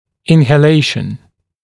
[ˌɪnhə’leɪʃn][ˌинхэ’лэйшн]вдыхание, ингаляция, аспирация